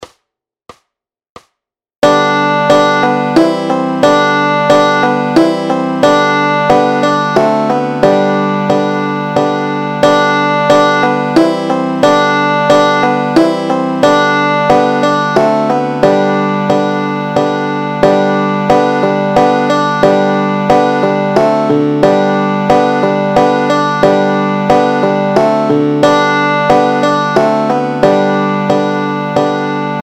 Noty, tabulatury, akordy na banjo.
Hudební žánr Vánoční písně, koledy